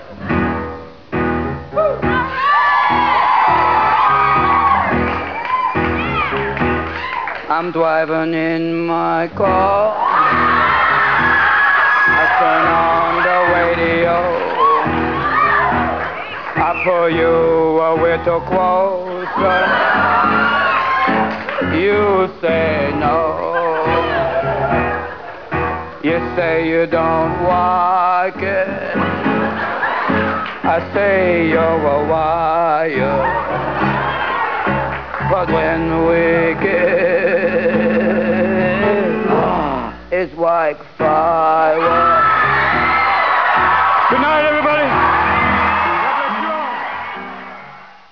Comedien wavs
Robin Williams - Elmer Fudd sings Springstein.
HA-fuddfire.wav